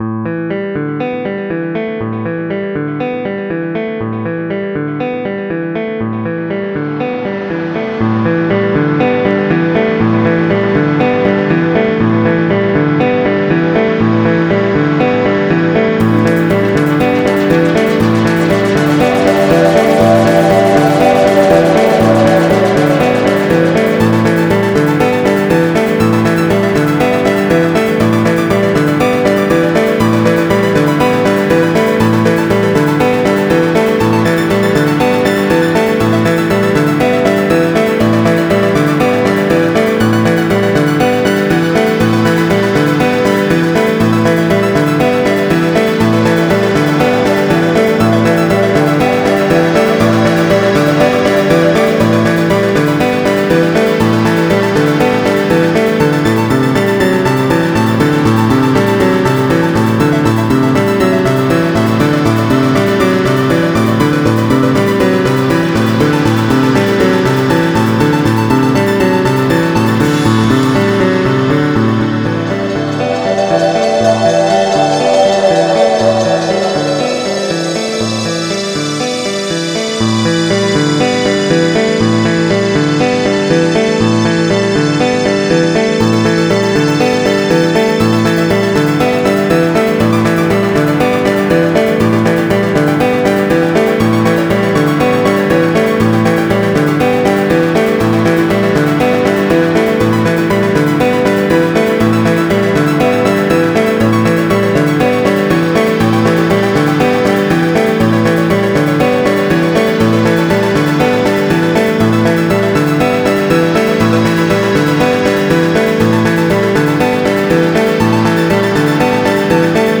NOTE: Every song below this line was made using the Nano Studio application and an ordinary laptop. Using this method takes an enormous amount of time to generate WAV files or alter existing audio samples of instruments such as violins, cellos, etc. in order to make a coherent tune of any kind.